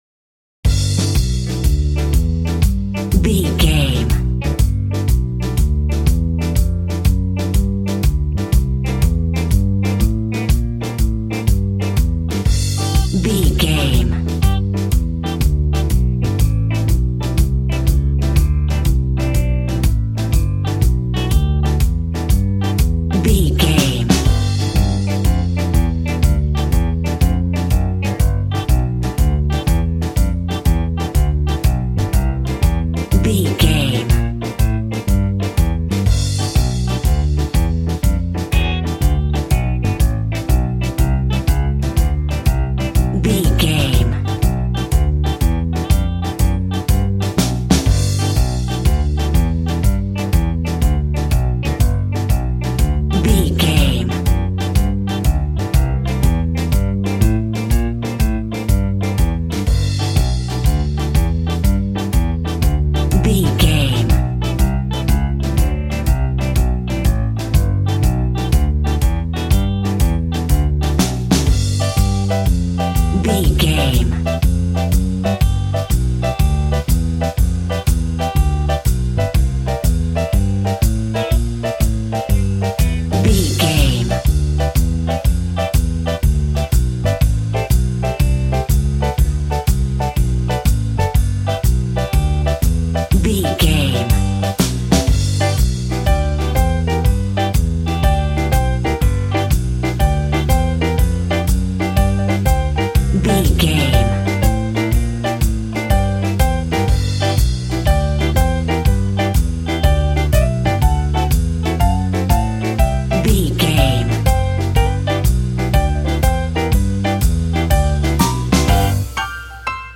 Ionian/Major
sad
mournful
bass guitar
electric guitar
electric organ
drums